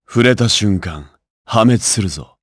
Lusikiel-Vox_Skill5_jp.wav